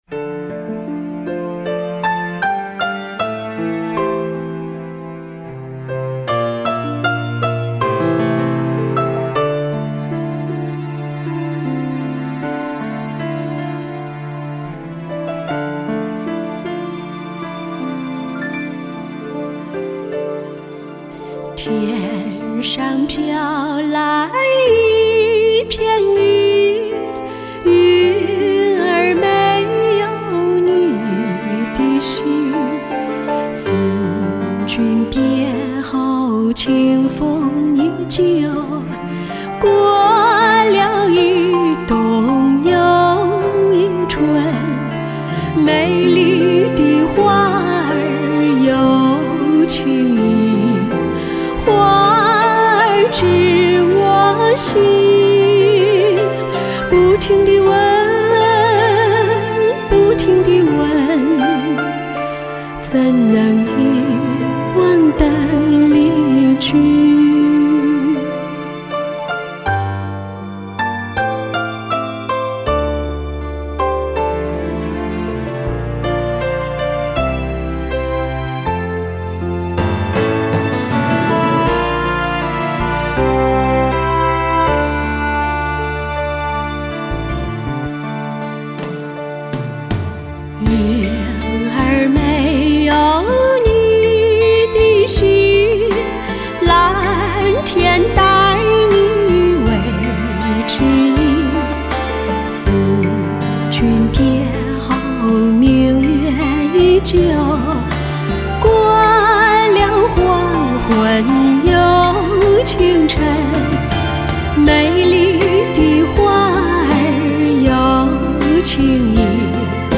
“邓氏唱腔”被认可 生就一副酷似邓丽君的声线